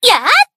BA_V_Asuna_Bunny_Battle_Shout_2.ogg